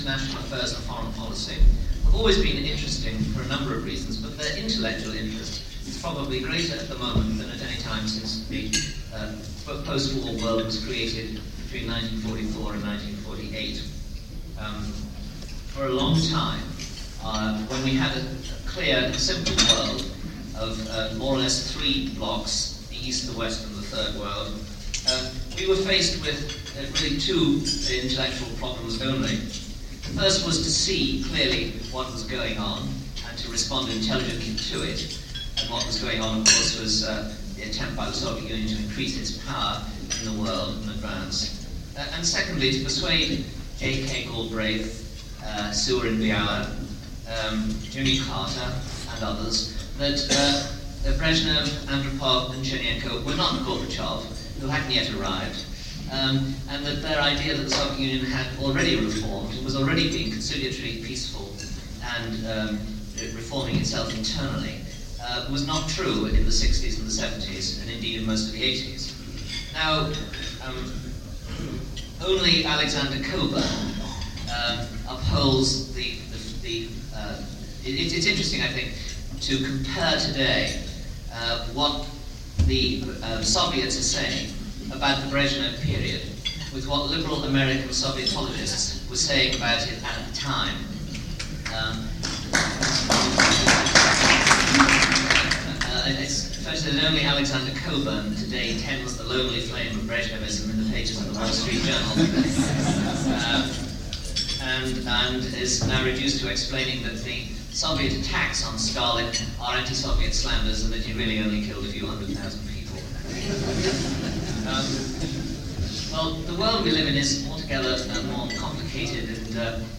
John O'Sullivan: National Security and the Defense of the West – Panel Introduction